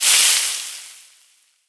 bfuzz_hit.ogg